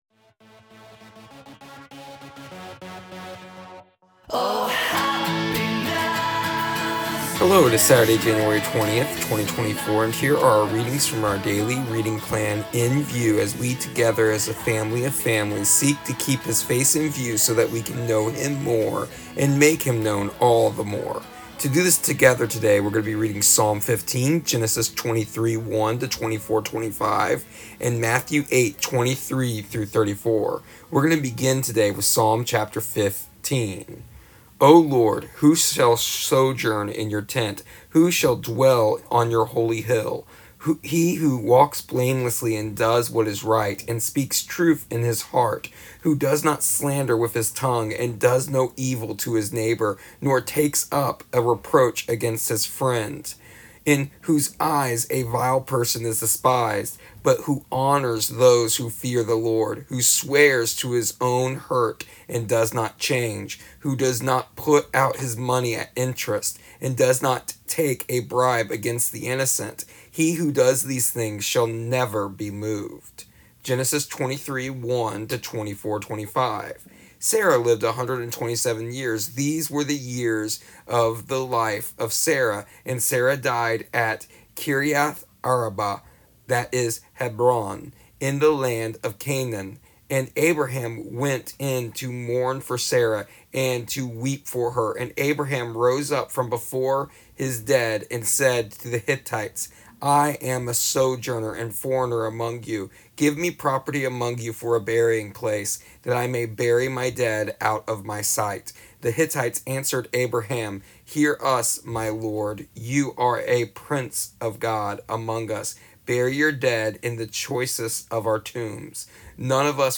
Here is the audio version of our daily readings from our daily reading plan “Keeping His Face in View” for January 20th, 2024.